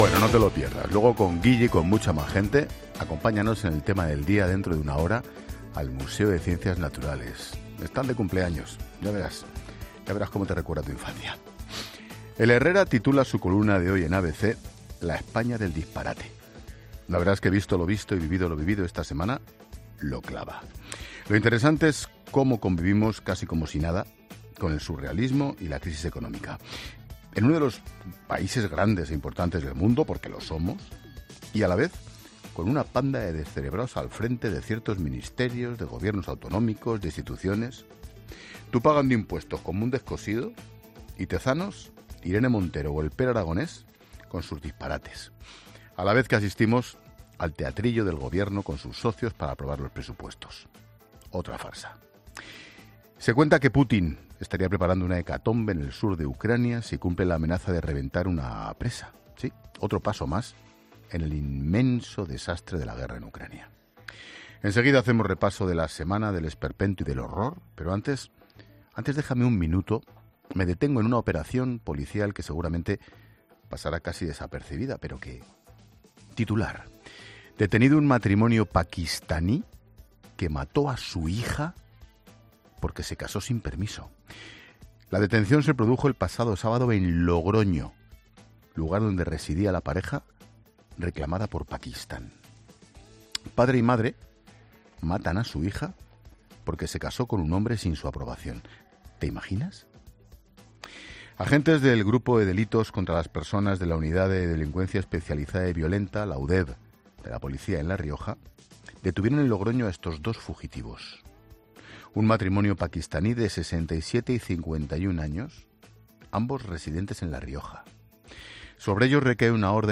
Monólogo de Expósito
El director de La Linterna analiza los últimos pasos de la Ley Trans, el asesinato de una niña pakistaní en Longroño y el artículo de Herrera en ABC